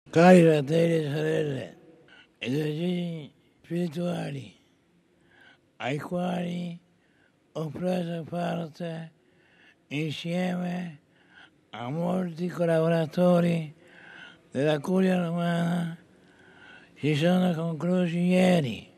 Minia nudžiugo, kai Šventasis Tėvas, kad ir sunkiai, bet pats kreipėsi į susirinkusiuosius.